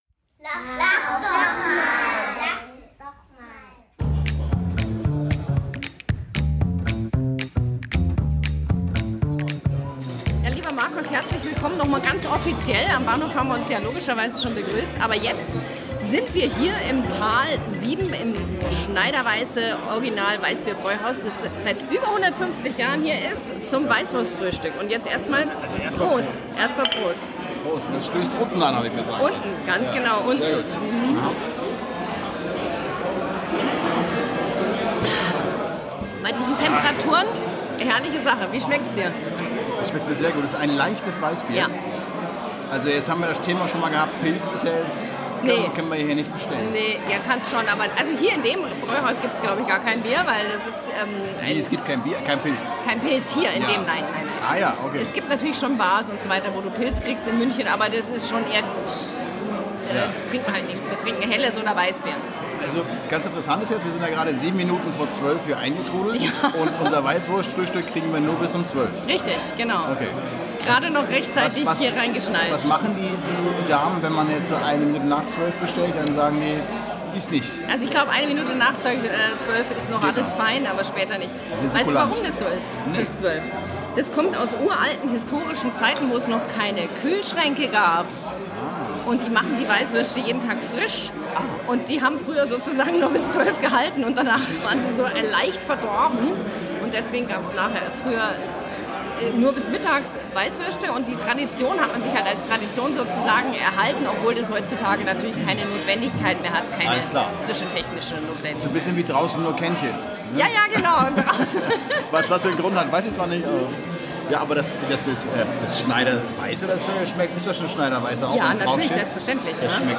Wir nehmen euch mit auf unsere Tour durch München und lassen euch an unseren Gesprächen teilhaben.